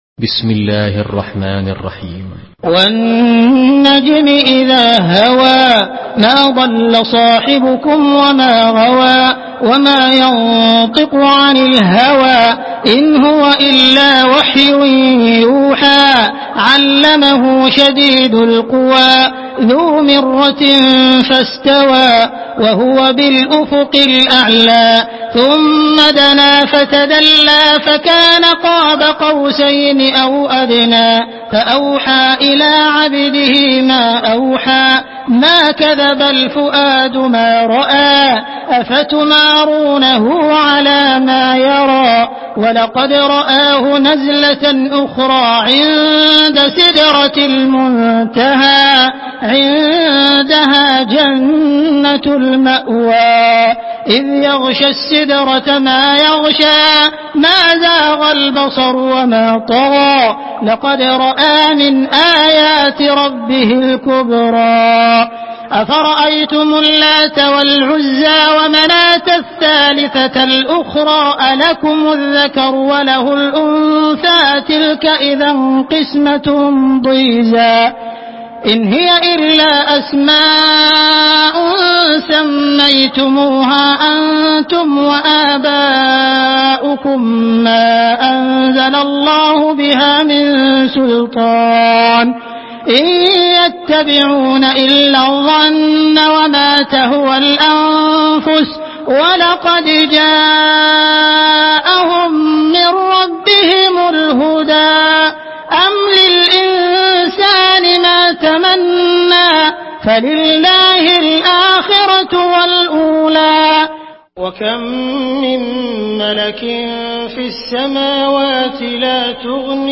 Surah النجم MP3 in the Voice of عبد الرحمن السديس in حفص Narration
Surah النجم MP3 by عبد الرحمن السديس in حفص عن عاصم narration.
مرتل